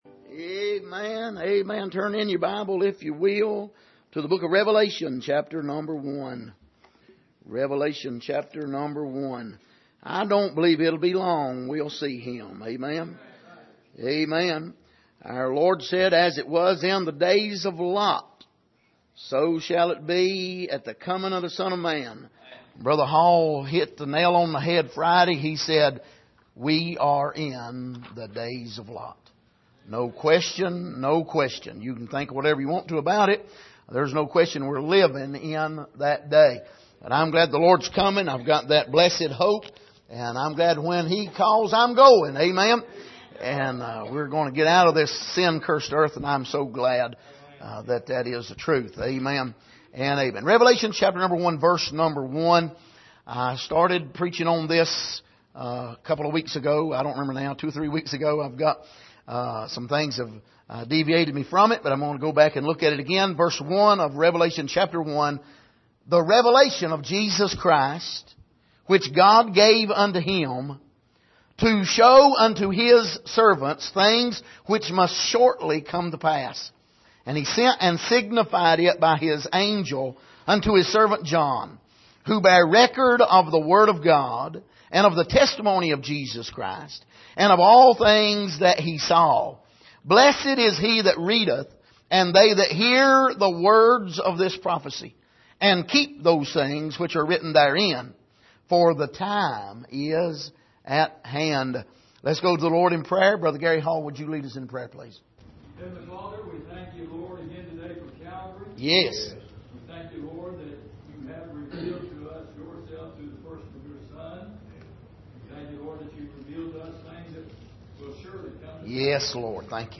Series: Studies in Revelation Passage: Revelation 1:1-3 Service: Sunday Morning